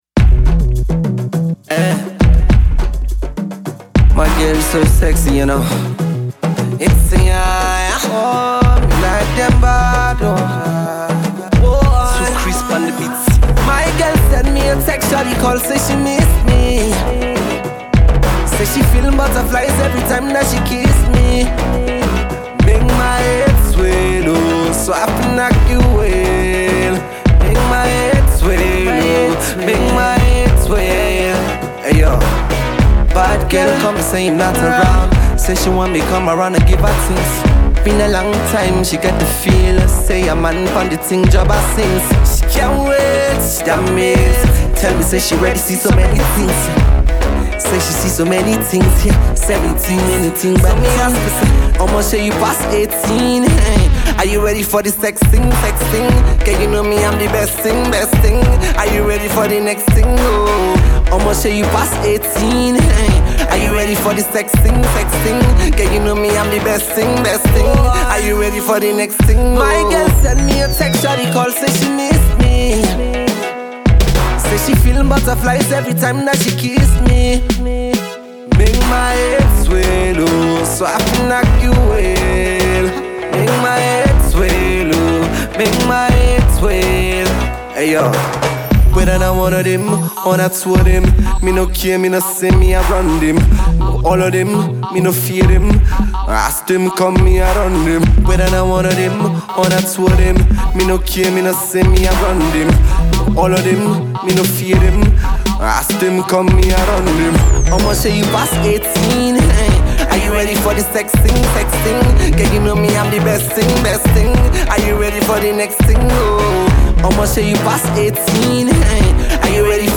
raunchy new dancehall-inspired joint